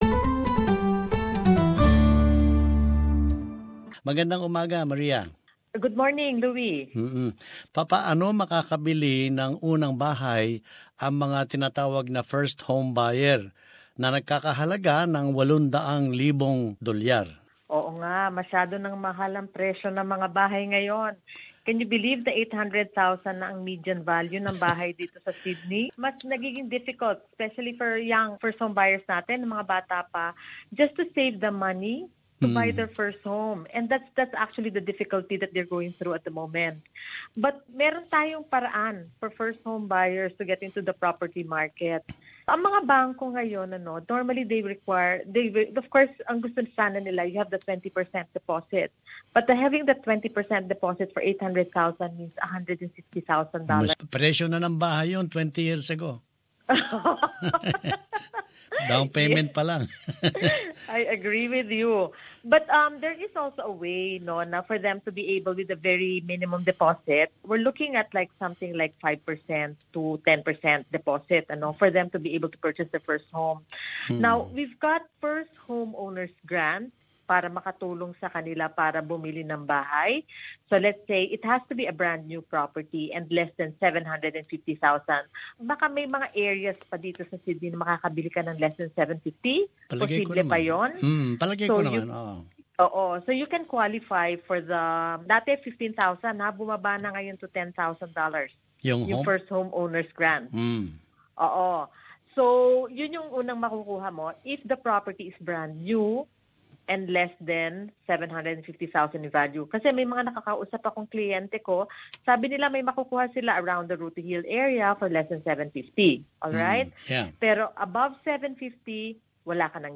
panayam